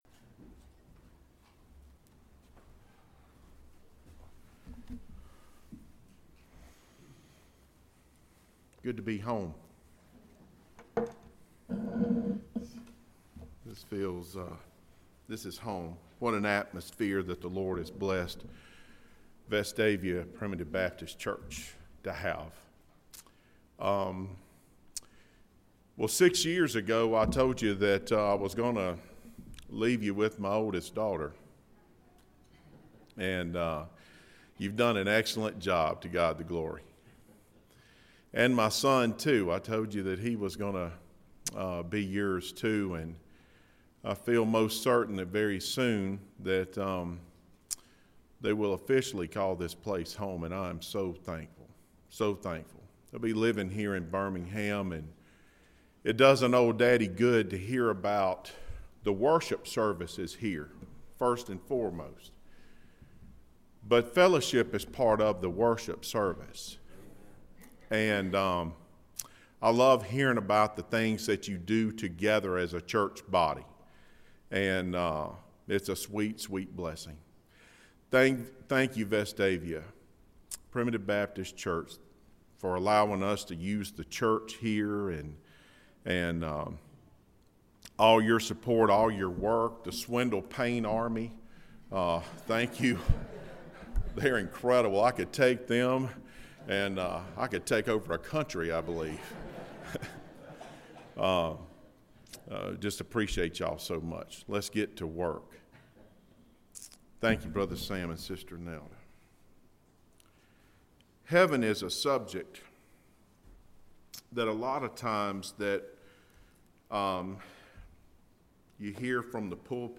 Sermons - Guest Preachers